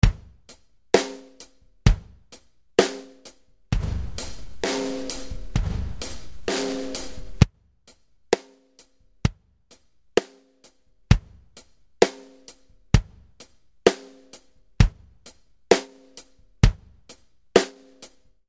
This is the smartest compressor you'll ever need for general use.
Each sequence is in a dry version and then effected in different ways.